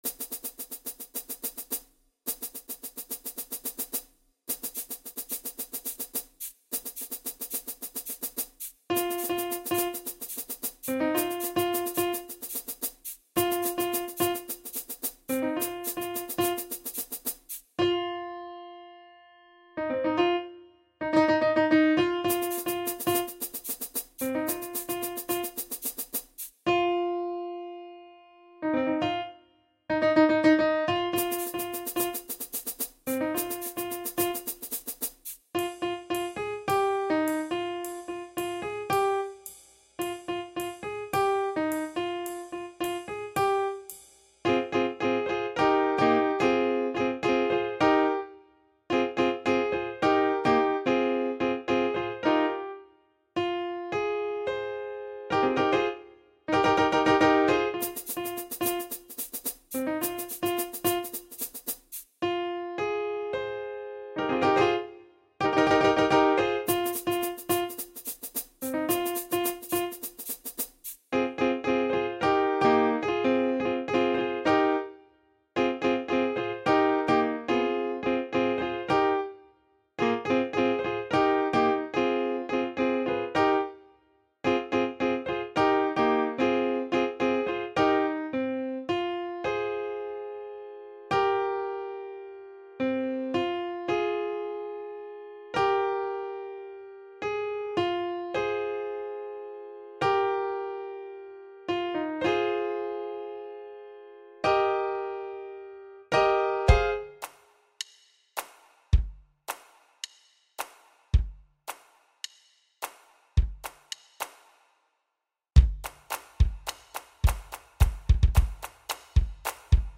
Elementary School Honor Choir Recording Tracks